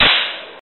smack-01.mp3